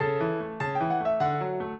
piano
minuet1-11.wav